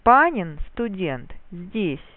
Мелодическая схема обособления (с падением тона)